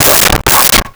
Dog Barking 04
Dog Barking 04.wav